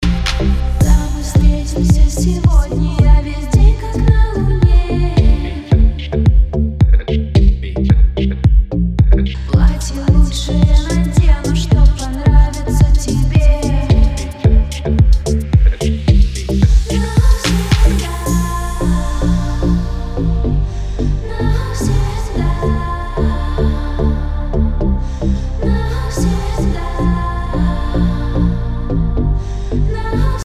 • Качество: 256, Stereo
женский вокал
dance